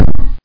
torpedo.mp3